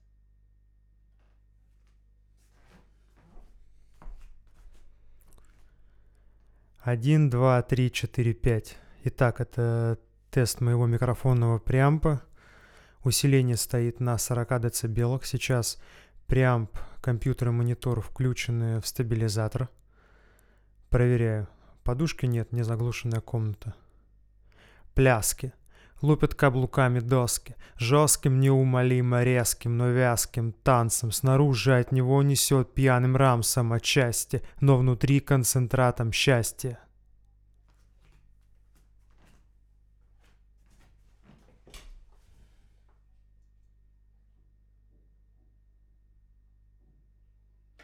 Гудит предусилитель
По звуку мне он очень понравился, но сразу же стал заметен негромкий, но различимый гул.
Гул, как мне показалось, специфический и возможно, знающим людям сразу укажет где искать причину. После выключения фантомного питания гул исчезает. Тракт: Neuman tlm103 -> Тот самый Neve -> (E-mu 1212m; RME Babyface Pro FS: не помню на что записан конкретно этот пример, на гул это никак не влияло).